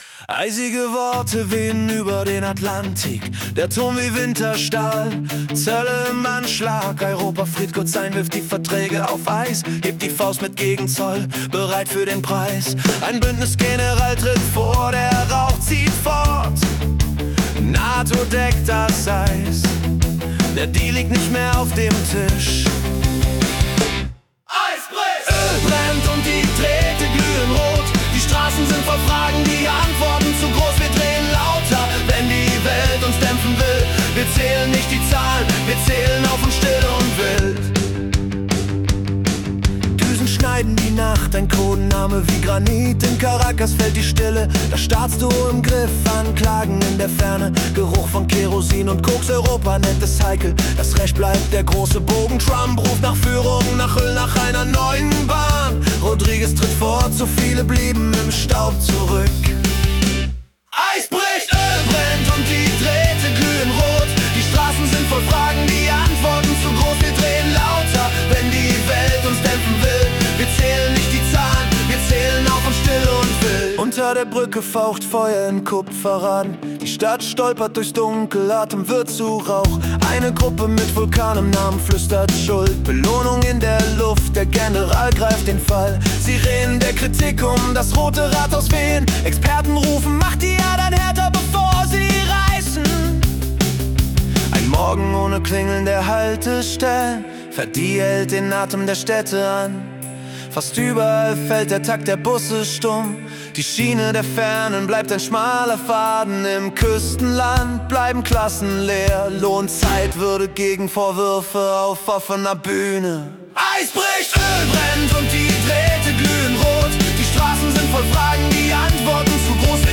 Februar 2026 als Rock-Song interpretiert.